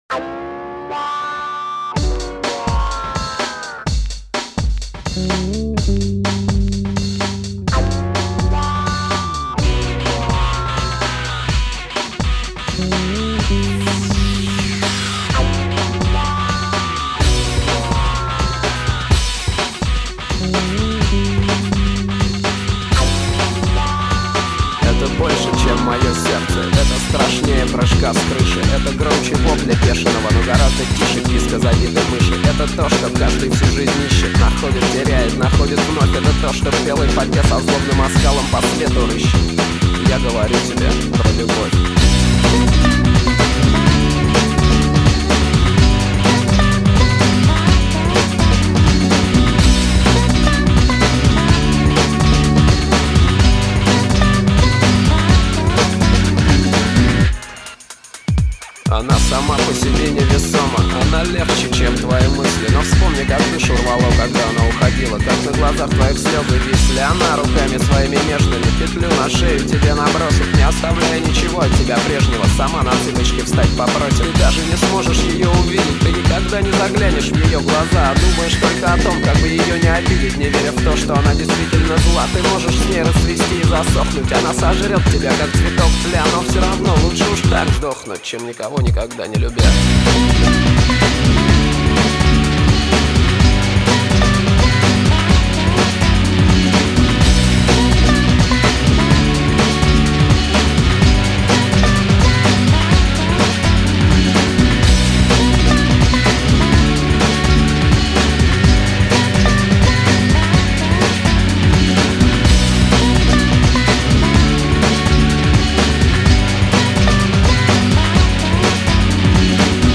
Файл в обменнике2 Myзыкa->Русский рок